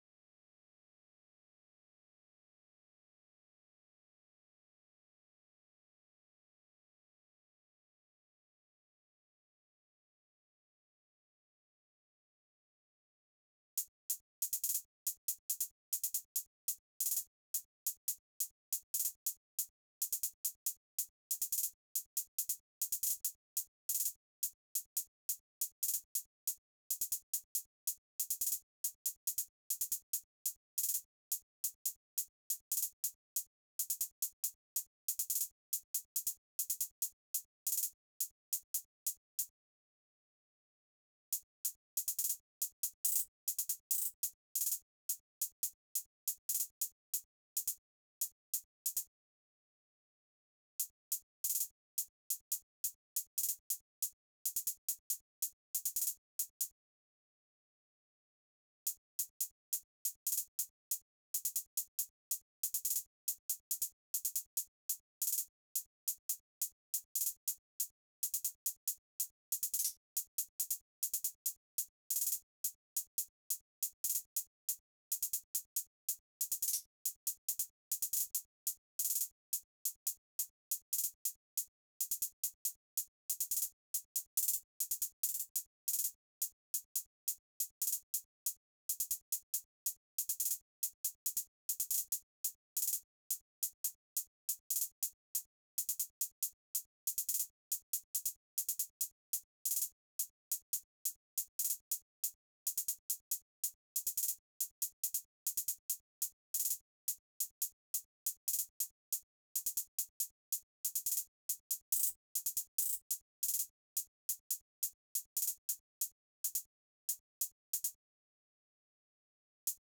Closed Hats
GATTI_HI HAT 2.wav